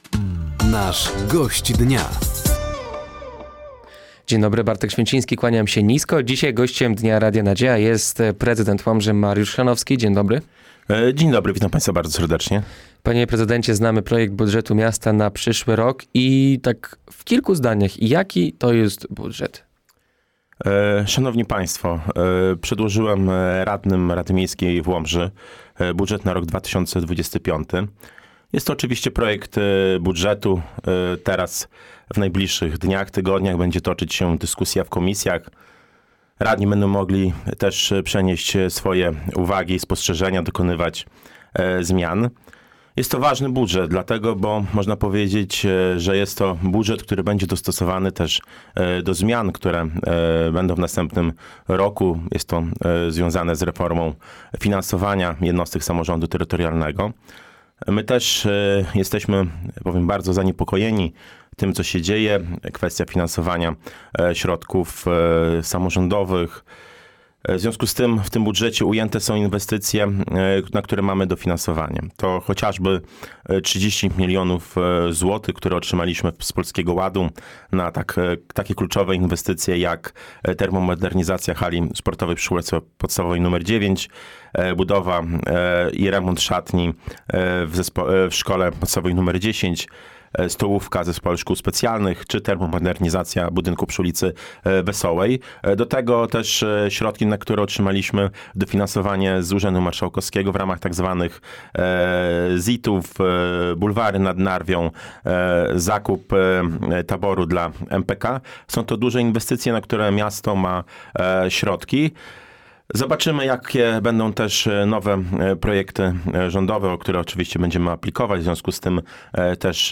Gościem Dnia Radia Nadzieja był prezydent Łomży, Mariusz Chrzanowski. Głównym tematem rozmowy był projekt budżetu miasta na przyszły rok.